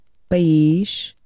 Ääntäminen
France (Paris): IPA: [ɛ̃ pe.ji]